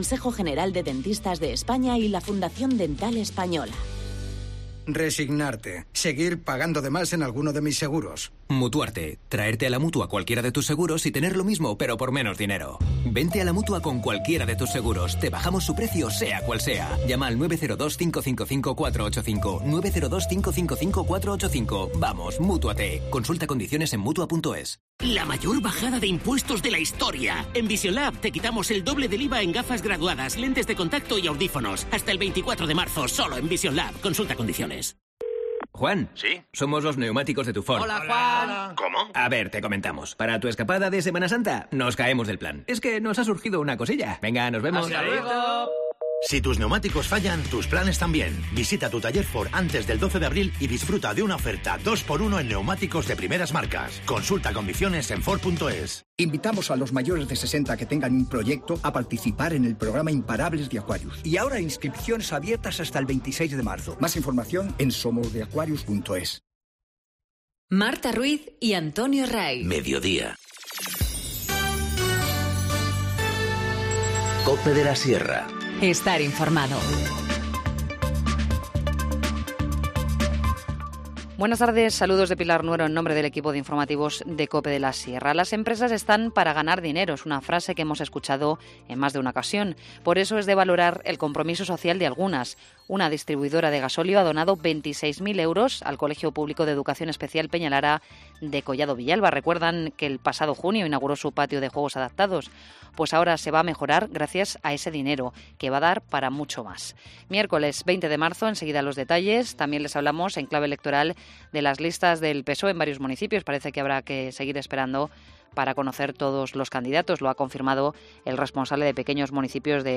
Informativo Mediodía 20 marzo 14:20h